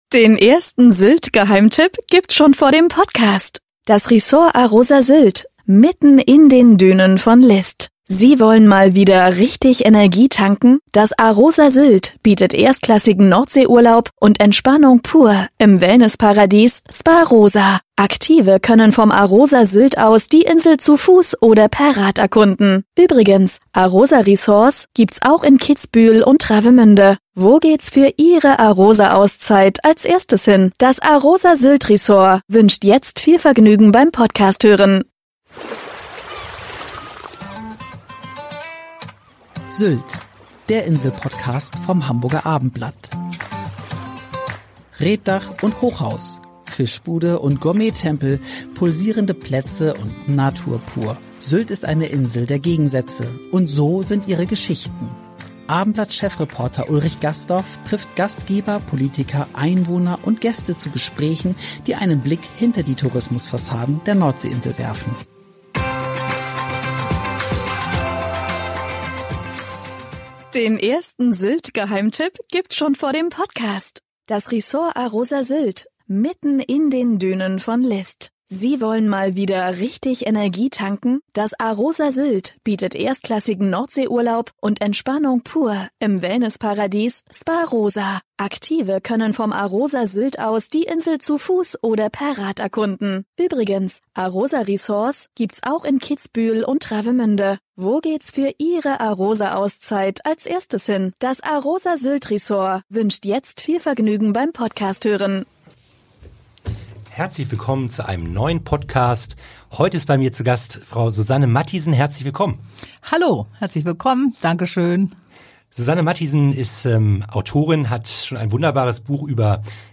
Gespräche, die einen Blick hinter die Tourismus-Fassaden der Nordsee-Insel werfen